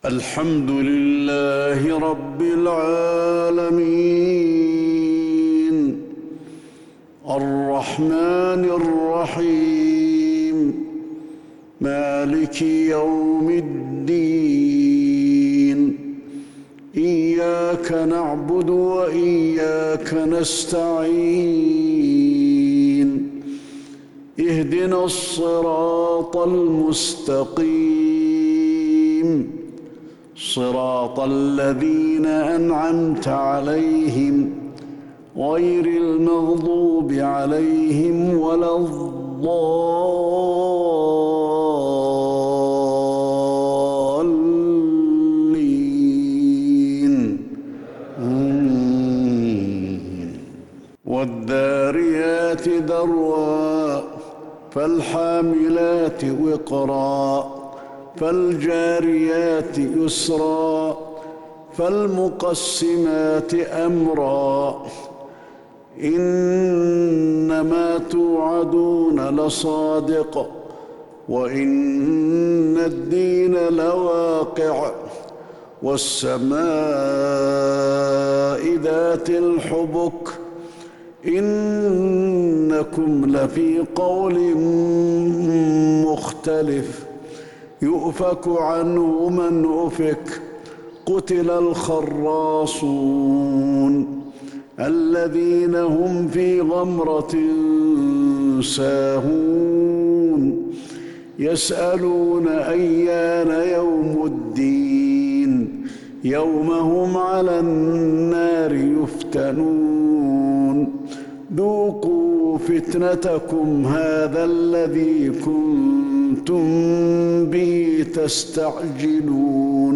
صلاة الفجر للشيخ علي الحذيفي 2 ذو الحجة 1442 هـ
تِلَاوَات الْحَرَمَيْن .